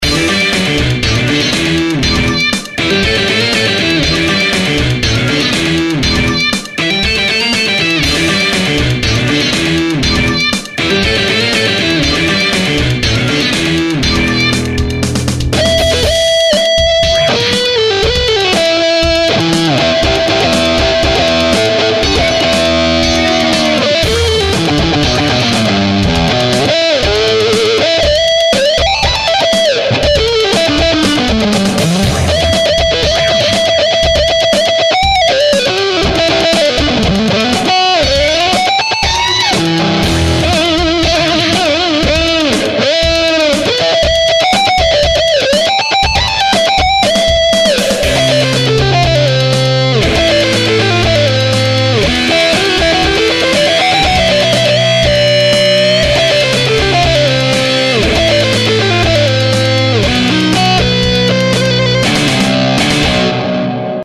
●AmpRoomRock●1965Combo●Dist2VX